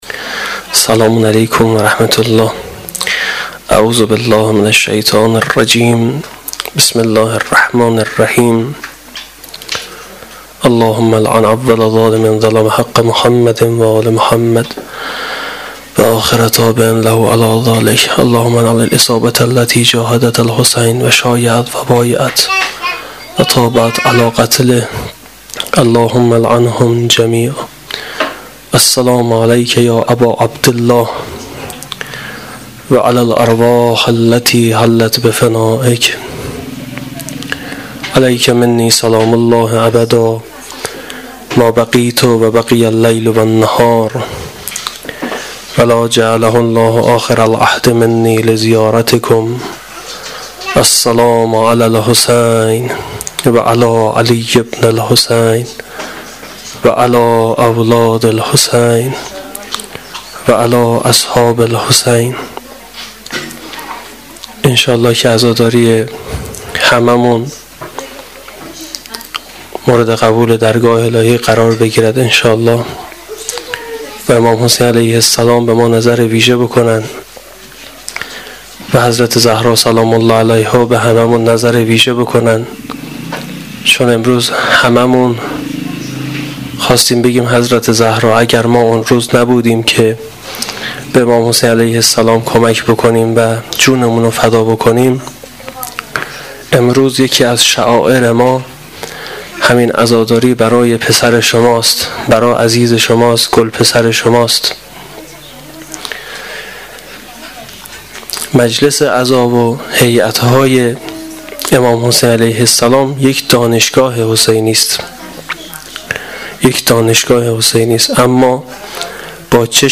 سخنرانی شب یازدهم محرم الحرام 1396 (شام غریبان)